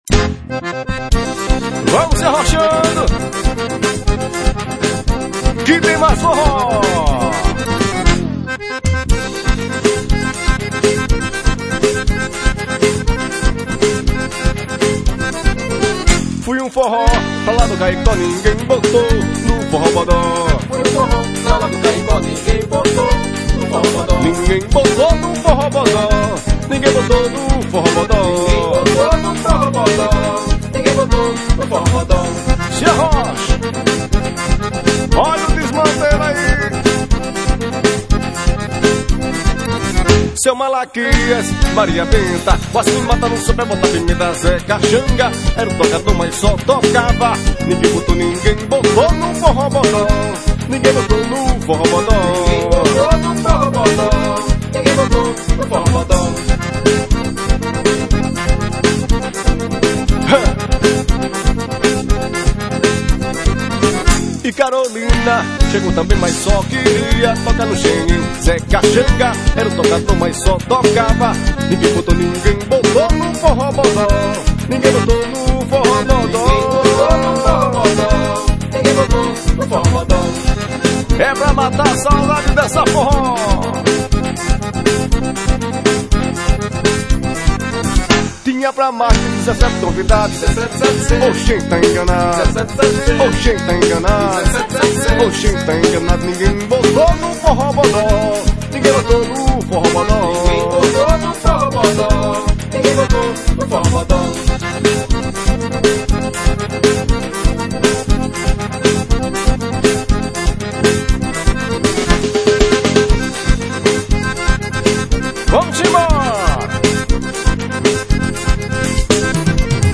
Forró Pé de Serra